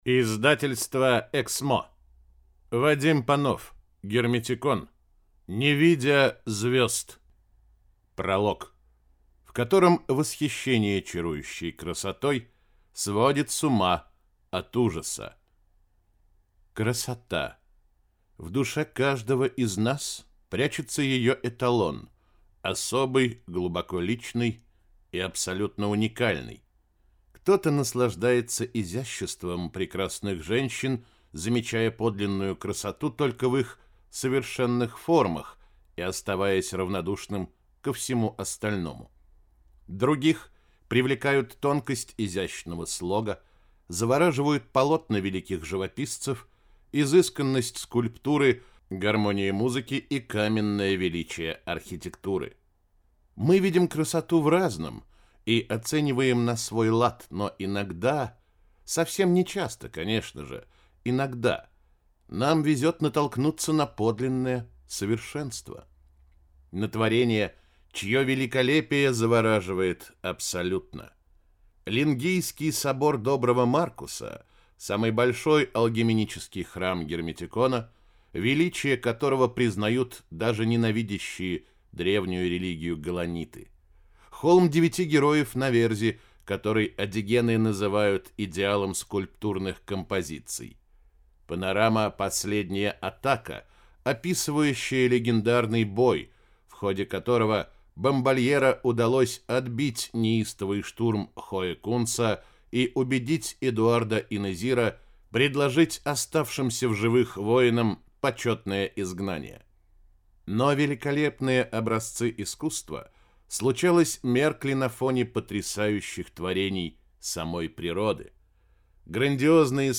Аудиокнига Не видя звёзд - купить, скачать и слушать онлайн | КнигоПоиск